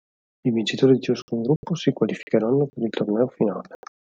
Read more (masculine) tournament (masculine) competition (sports) Frequency C1 Hyphenated as tor‧nè‧o Pronounced as (IPA) /torˈnɛ.o/ Etymology From Old French tornoi.